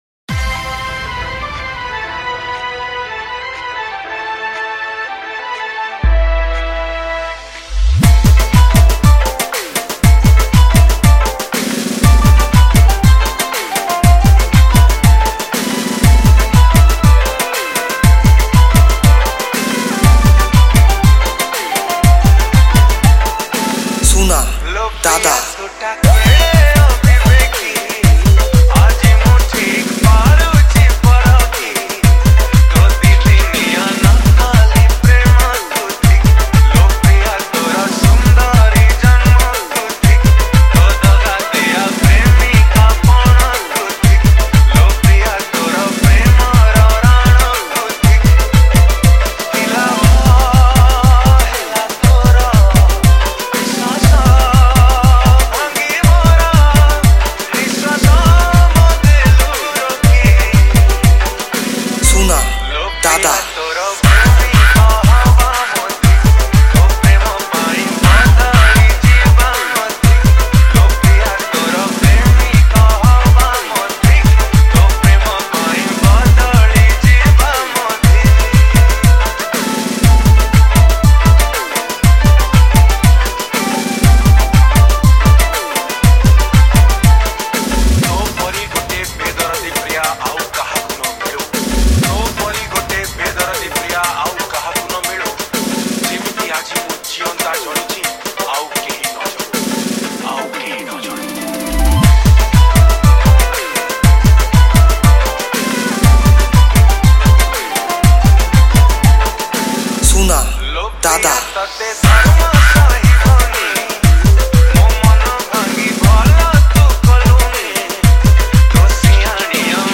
Category:  New Odia Dj Song 2020